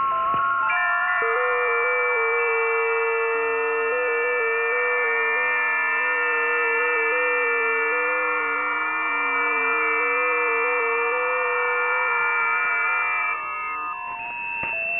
websdr_test8.wav